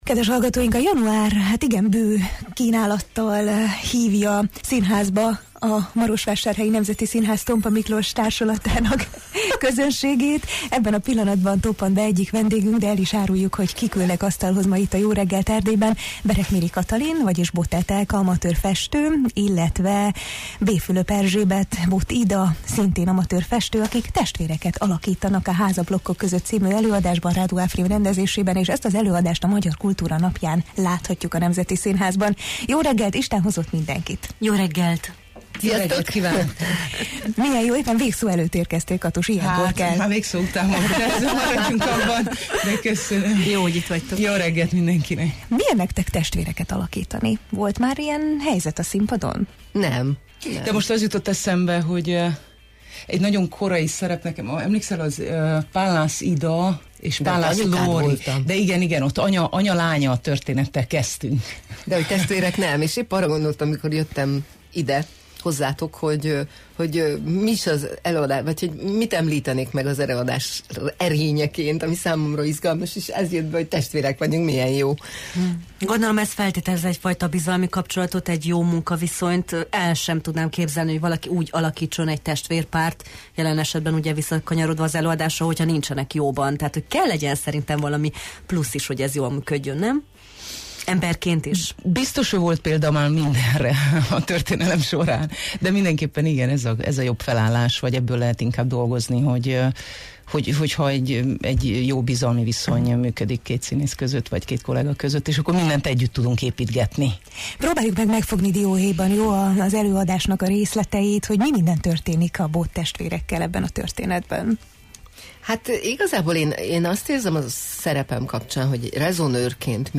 színművészek voltak a Jó reggelt, Erdély! vendégei: